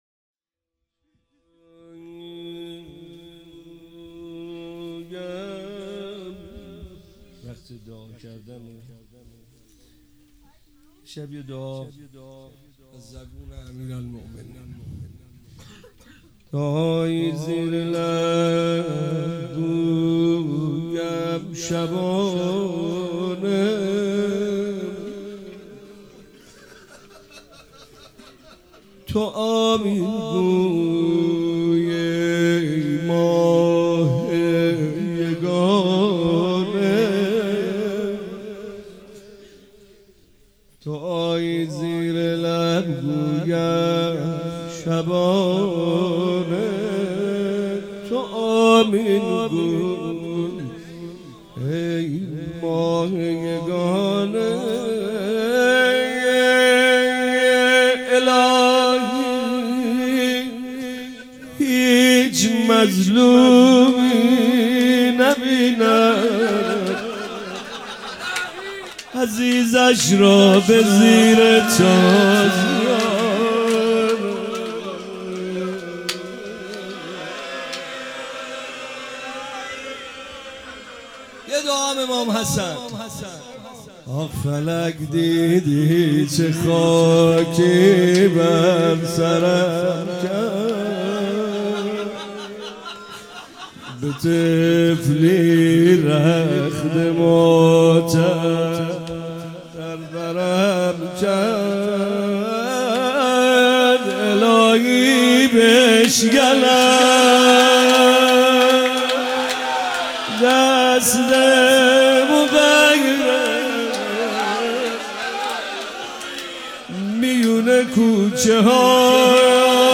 فاطمیه 96 - شب چهارم - مدح امیرالمومنین سلام الله علیه